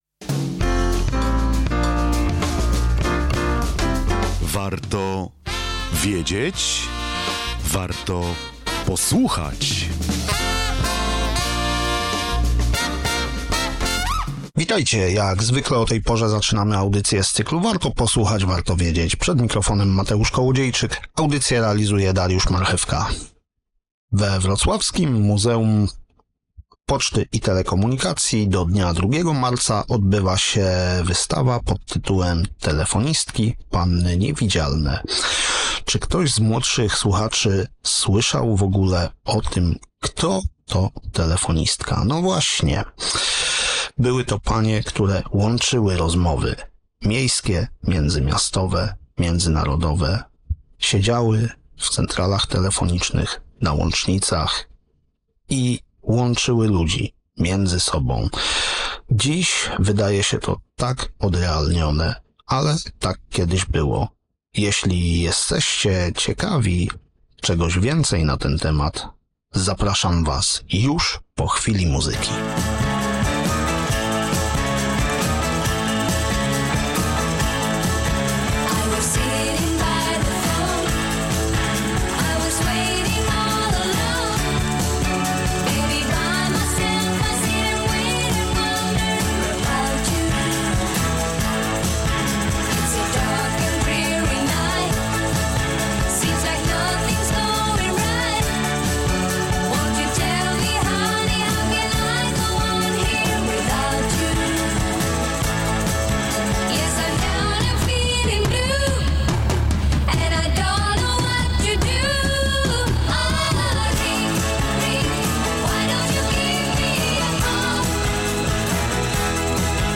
Rozmowa z Paniami TelefonistkamiMuzeum Poczty i Telekomunikacji